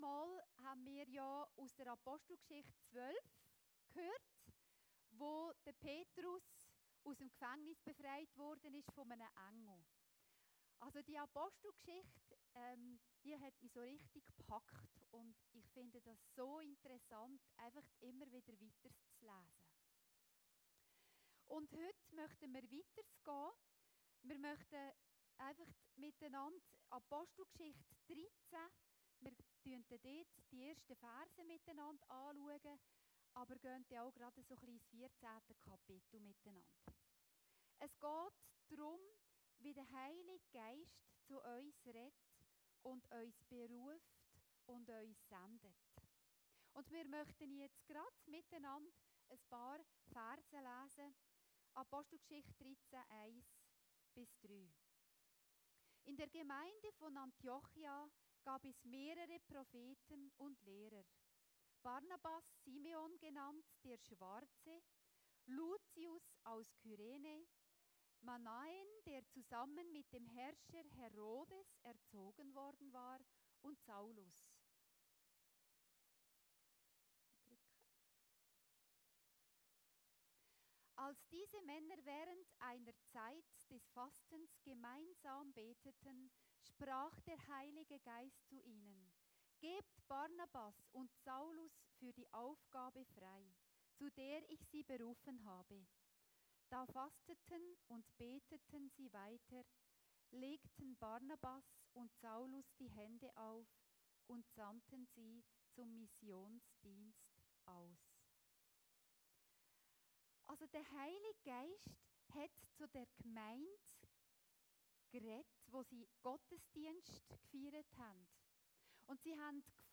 Predigten Heilsarmee Aargau Süd – VOM HEILIGEN GEIST BERUFEN